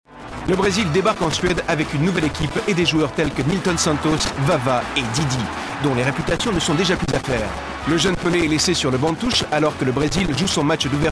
Kein Dialekt
Sprechprobe: Sonstiges (Muttersprache):
french voice over talent